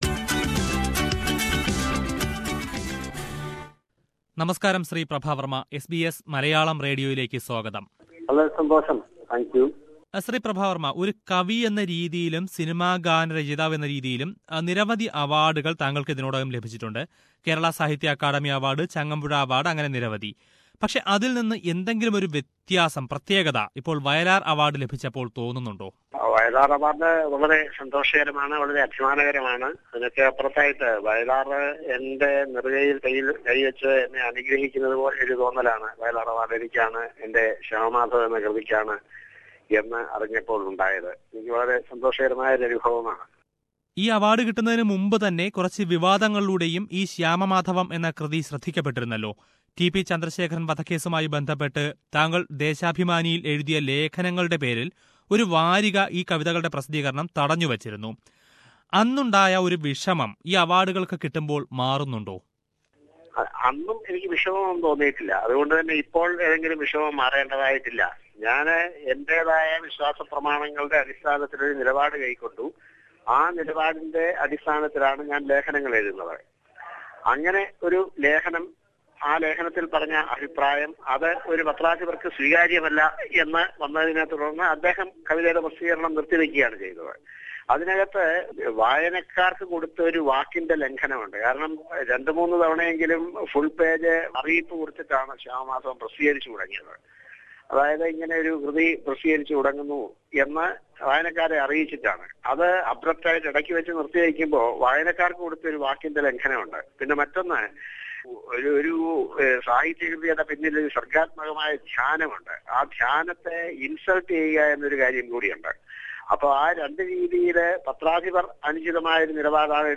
Hats off to him for his wonderful and fascinating poetry Shyama Madhavam, which won the Vayalar Award for the best poem in Malayalam this year. SBS Malayalam speaks to Prabha Varma who shares his ideology on love and the inspiration behind his famous works…